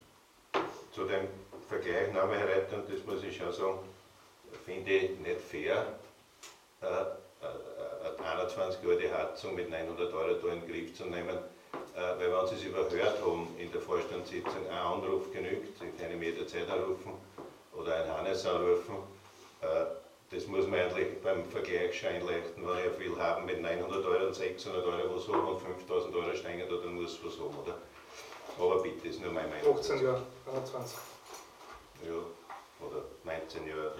Zu einer mehr als fragwürdigen Abstimmung über den Ankauf einer gebrauchten Hackschnitzelheizung kam es in der Gemeinderatssitzung vom 16. September.
In der nachfolgenden Diskussion stellte der Bürgermeister klar, dass ein Vergleich dieser Angebote nicht möglich sei, da die Anlage des ÖVP-Ortsparteiobmanns mit einem Alter von lediglich 7 Jahren wesentlich jünger ist und das den höheren Preis rechtfertige (Nachzuhören